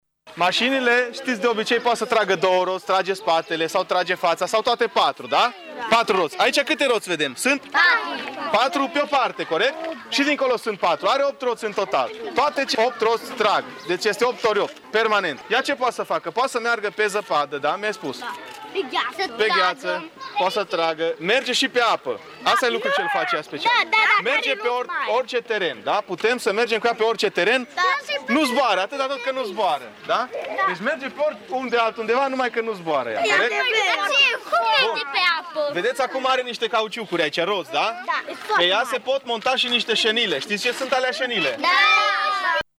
Pompierii le-au explicat pe înţelesul lor la ce sunt folosite acestea: